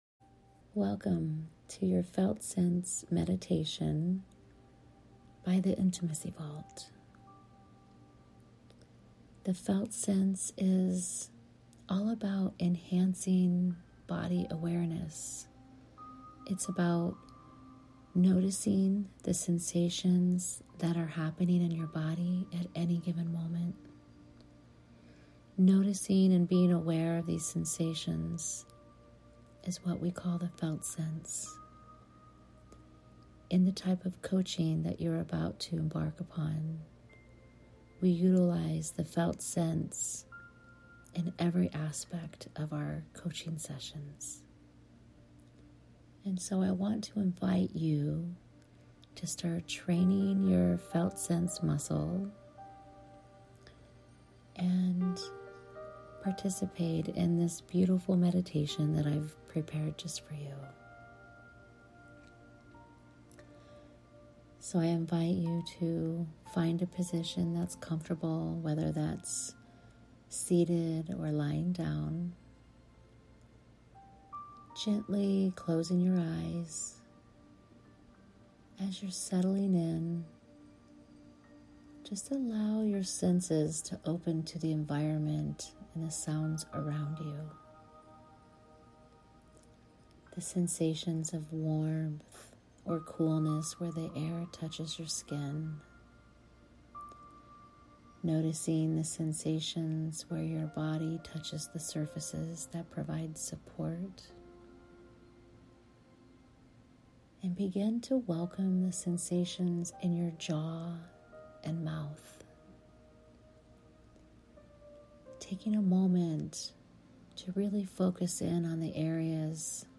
Felt Sense Meditation + Guide Resource